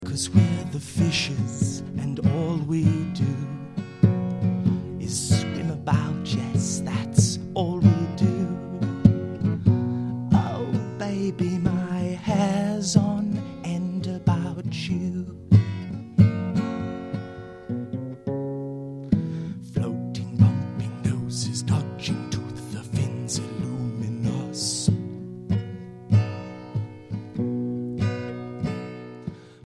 Ashington Folk Club - Spotlight 16 November 2006